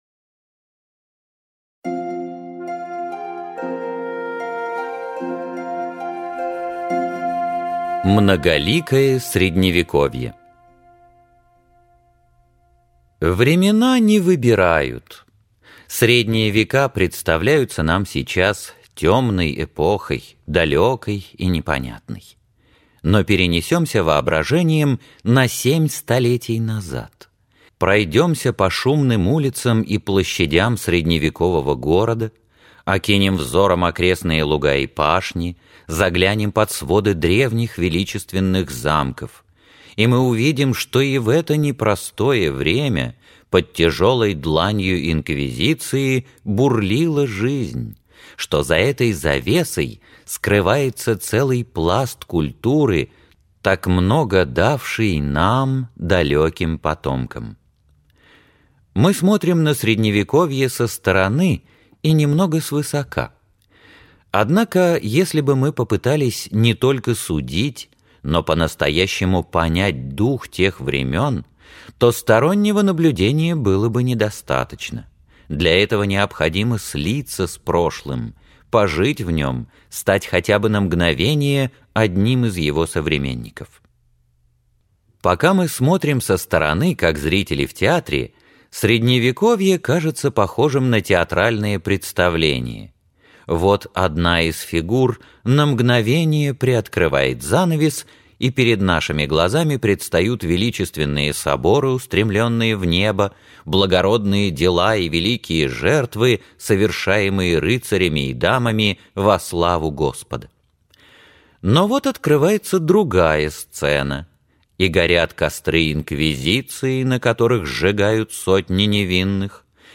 Аудиокнига Жизнь средневековых рыцарей | Библиотека аудиокниг
Прослушать и бесплатно скачать фрагмент аудиокниги